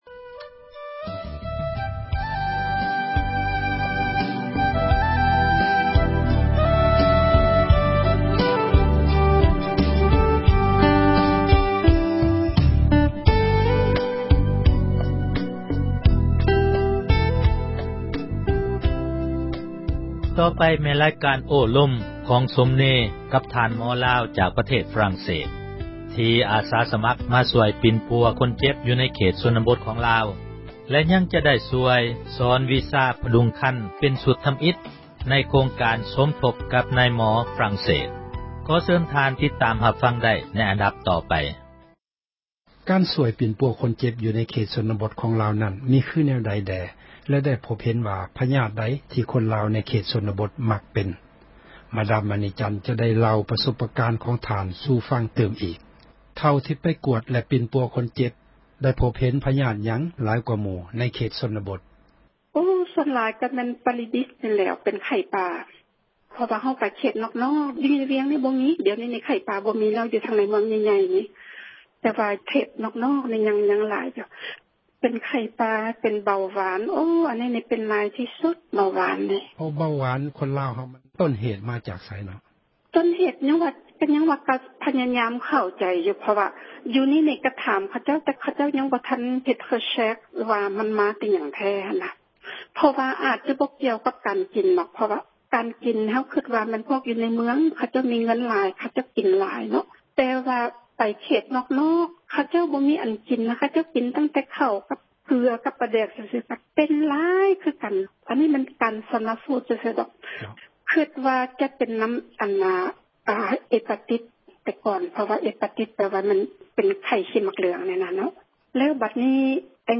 ໂອ້ລົມກັບທ່ານໝໍລາວ ຈາກຝຣັ່ງເສສ